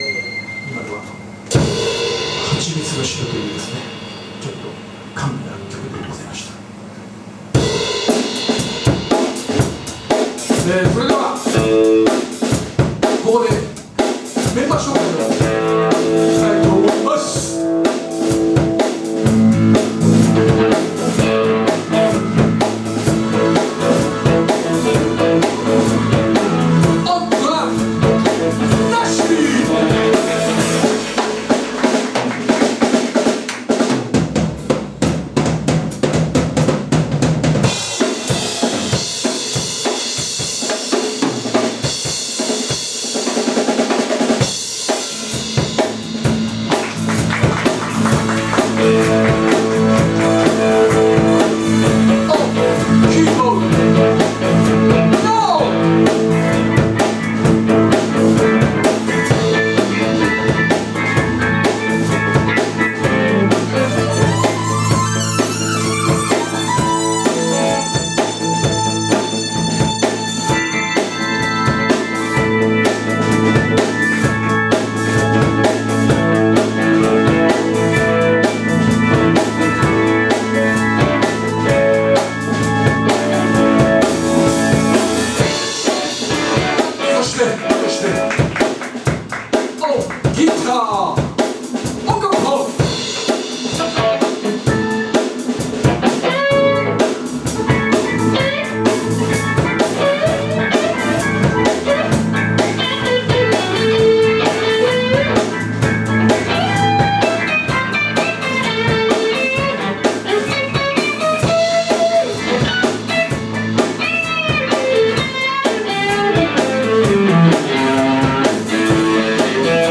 個人的に、コロナ禍になってからはスタジオに入ったりレコーディングしたりはしていたものの、お客さんの前でまともに演奏する機会は2年以上無かったんですが、ホント超久しぶりにライブやることが出来ました。
J-POPというか歌謡曲というか、個人的には尾崎豊とか浜田省吾を思い浮かべますけど、ま、そんなジャンルです。